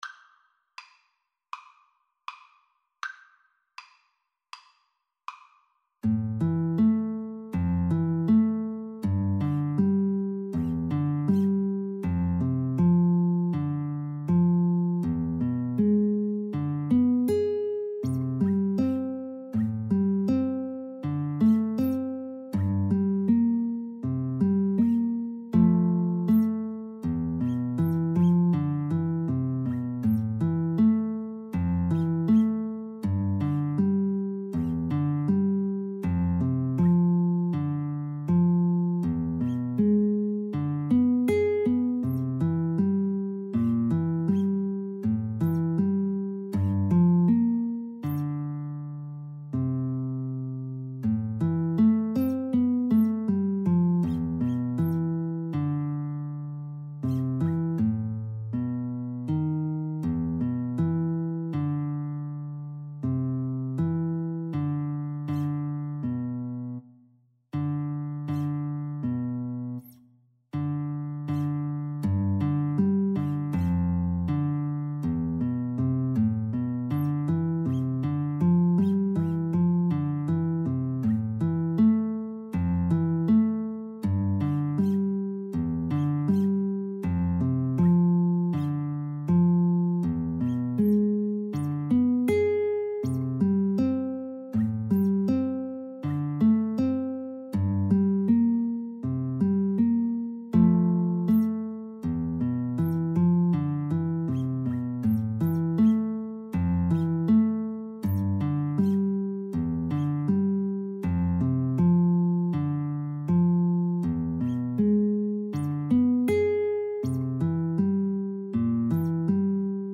4/4 (View more 4/4 Music)
Andante
Classical (View more Classical Violin-Guitar Duet Music)